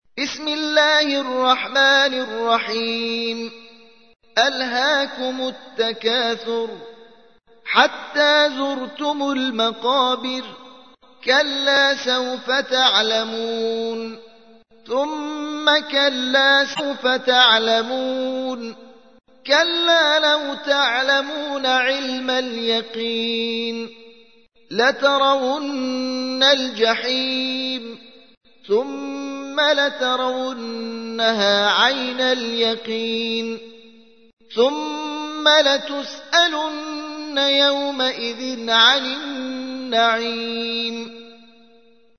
تحميل : 102. سورة التكاثر / القارئ محمد حسين سعيديان / القرآن الكريم / موقع يا حسين